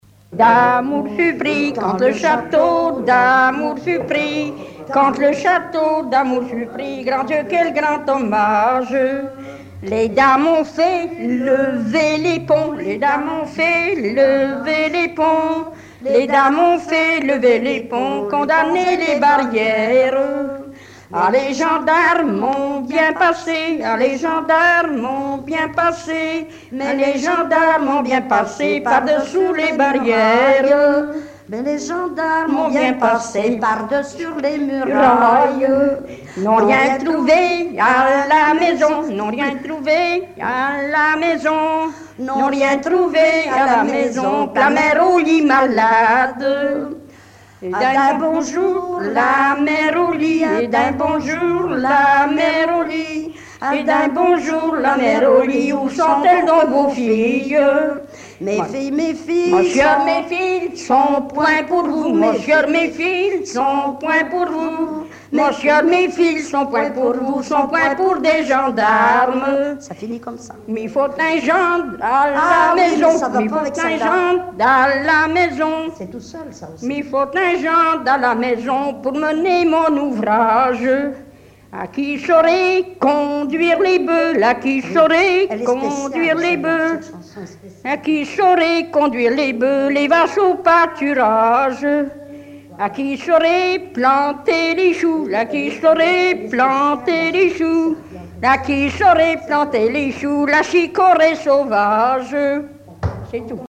danse : ronde : grand'danse
Genre laisse
Pièce musicale inédite